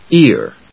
/íɚ(米国英語), íə(英国英語)/